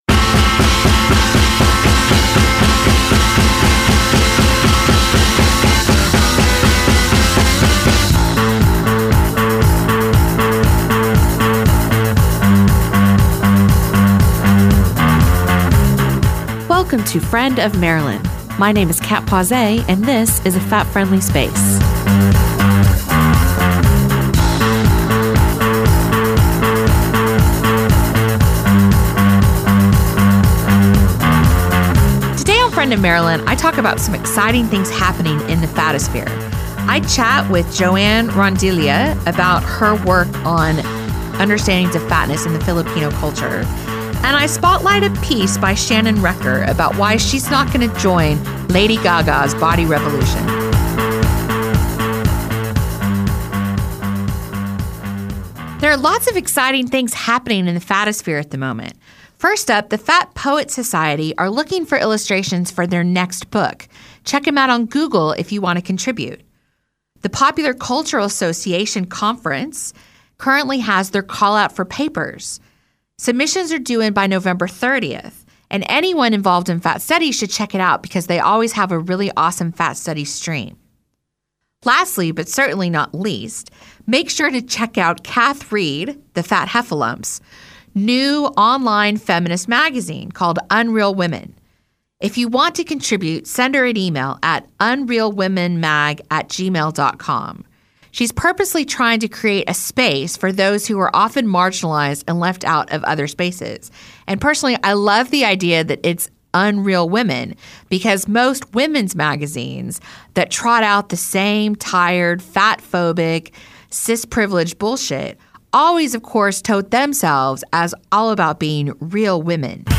Friend of Marilyn was listened to around the world on multiple platforms and was broadcast locally by Manawatū People’s Radio online and on 999AM.